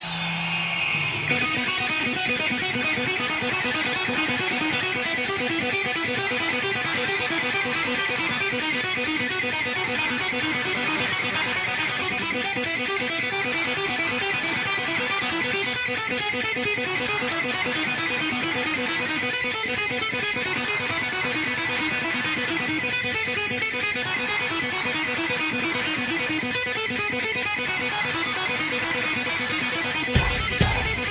Rock Intros
Das Ding ist ziemlich virtuos.